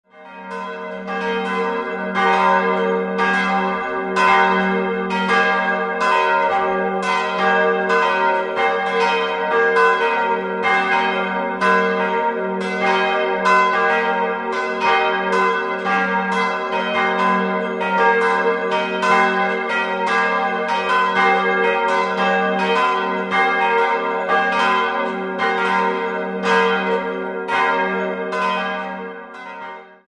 3-stimmiges TeDeum-Geläute: g'-b'-c'' Die Glocken g'+2 und b'+2 wurden bei Karl Czudnochowsky in Erding in Euphonlegierung gegossen.